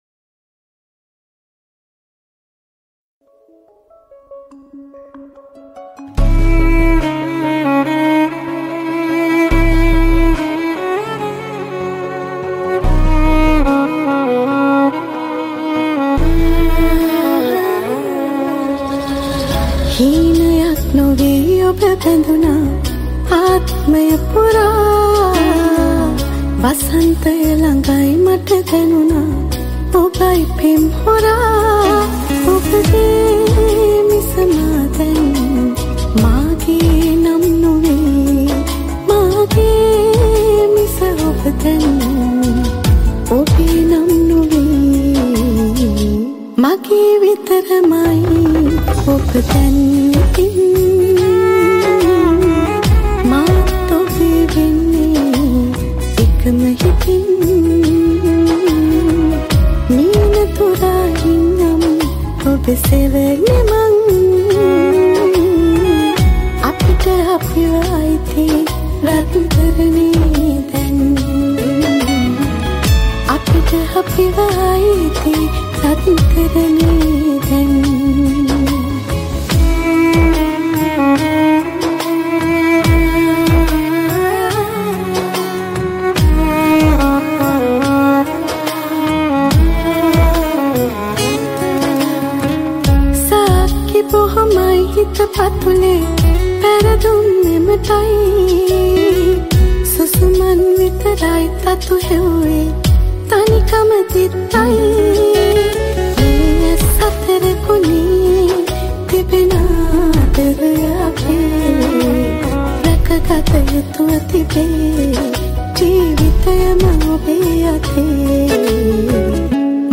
High quality Sri Lankan remix MP3 (4.4).